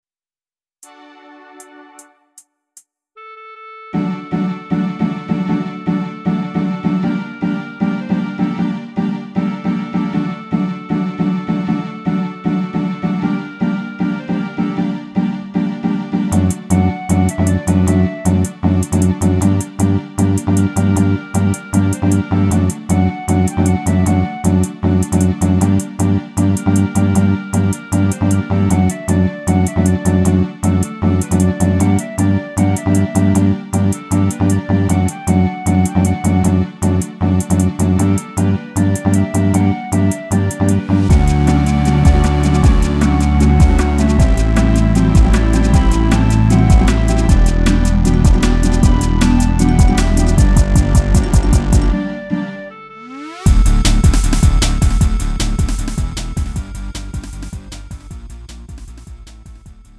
음정 원키 3:12
장르 가요 구분